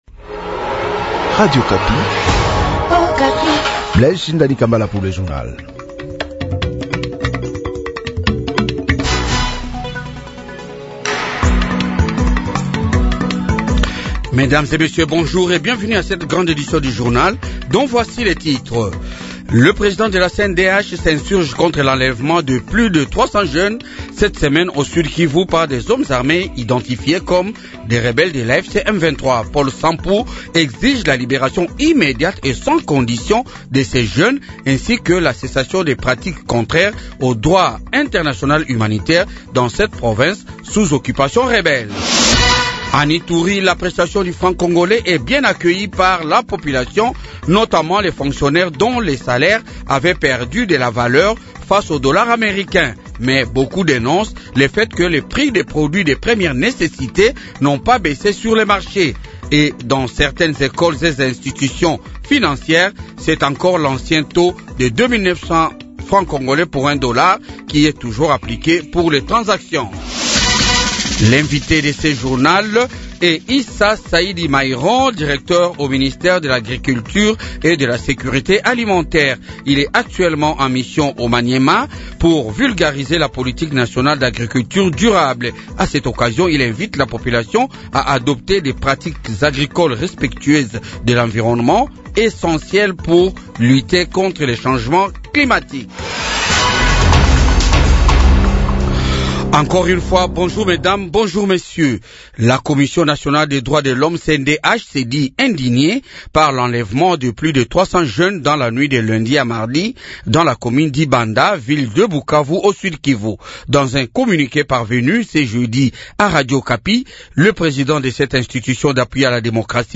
Journal Francais matin 8H